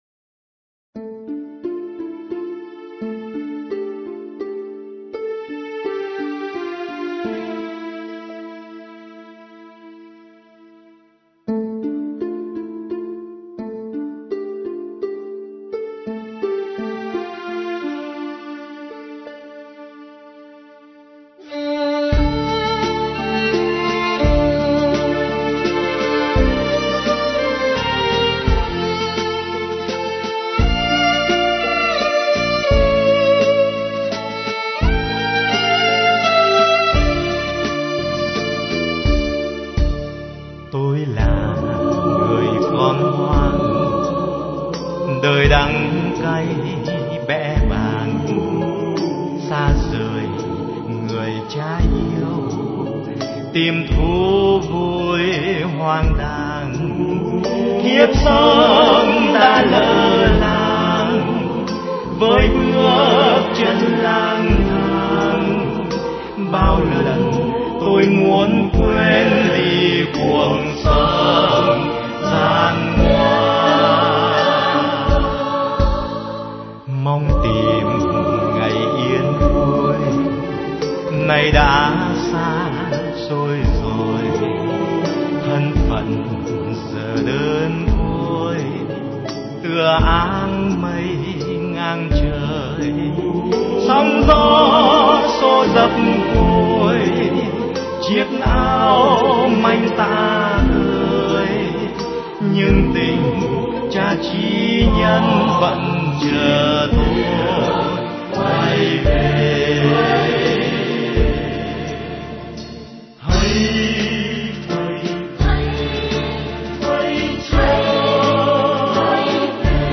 Giọng ca ngoài 50 rùi đó .